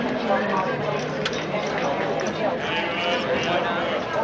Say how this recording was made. Here we host our database "IUEC (IIITD Urban Environment Context) database" which contains distress (scream and cry sounds) and sounds of 6 environmental contexts collected from mobile phones, movies and Internet.